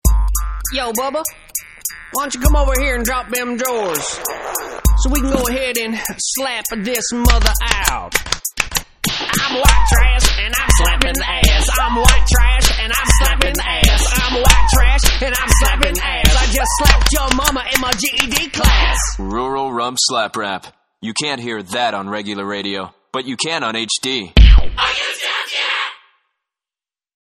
A former colleague now working at a major-market station sent along a CD containing some of the Clear Channel-produced spots now airing on stations nationwide promoting the arrival of digital radio.
hd-asslap.mp3